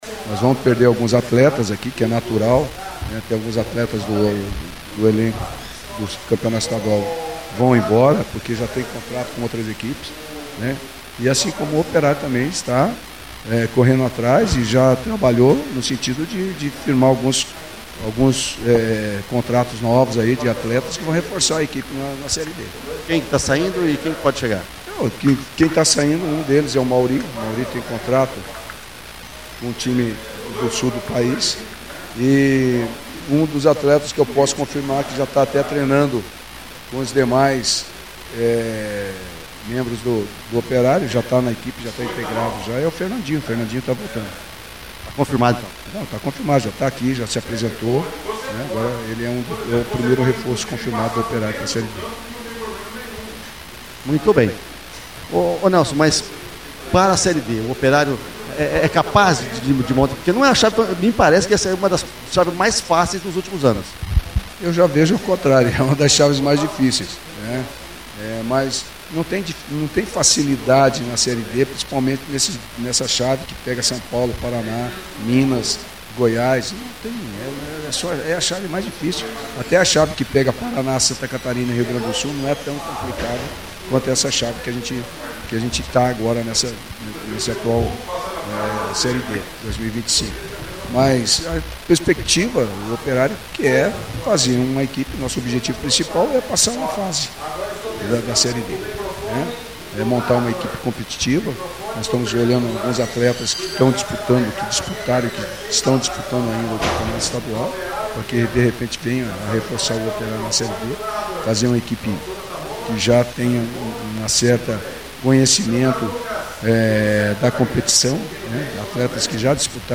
concedeu entrevista e falou sobre o Brasileirão 2025